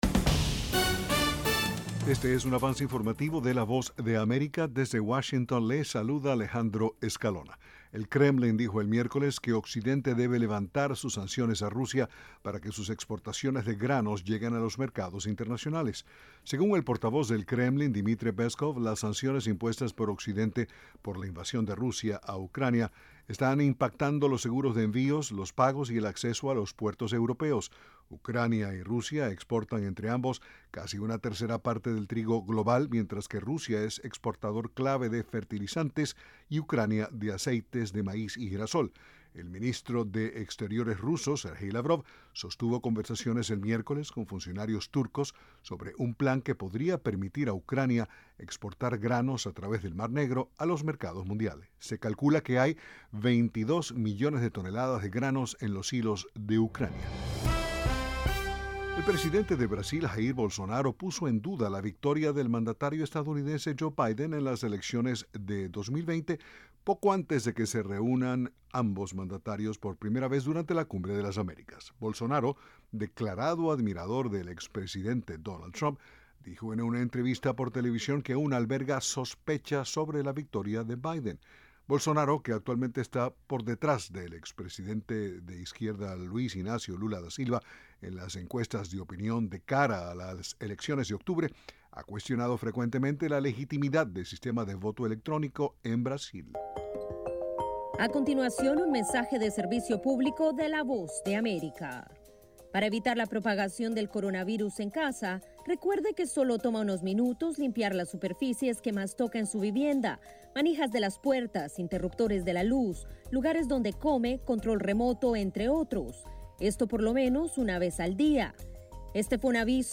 El siguiente es un avance informativo presentado por la Voz de América en Washington.